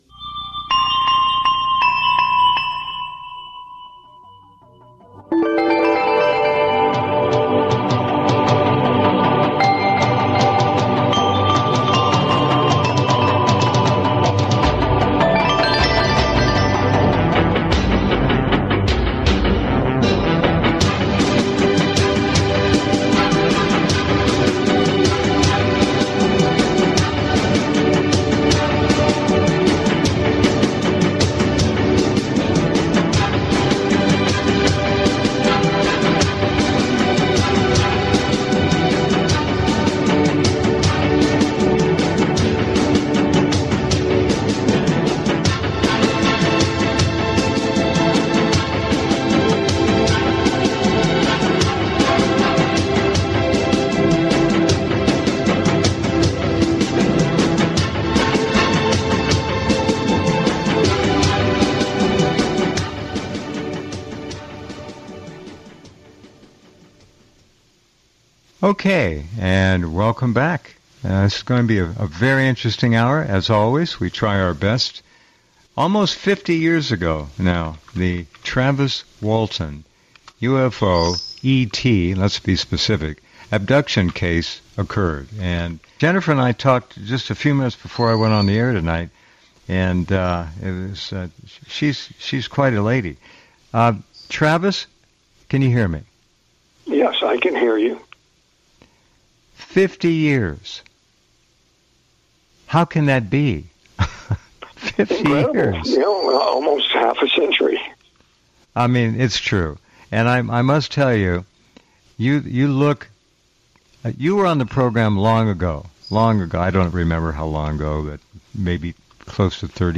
Media - Podcast Interviews